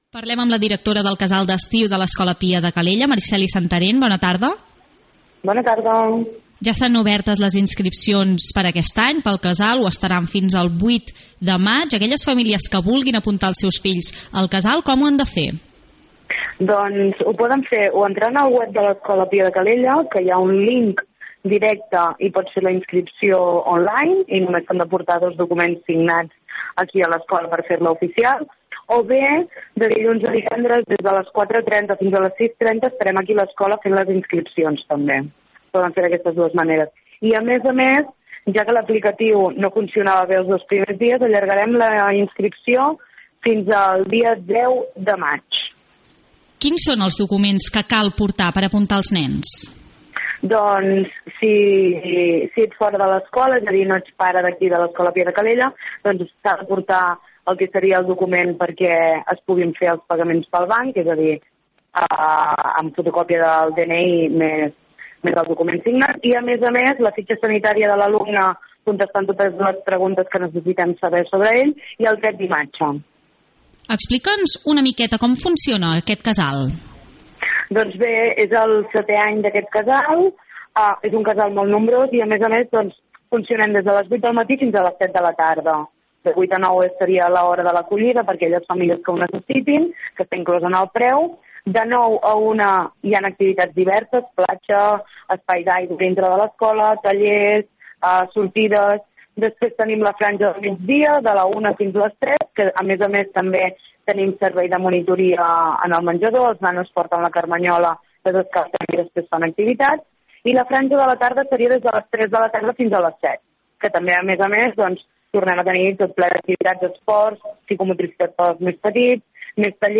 En una entrevista a l’Info Vespre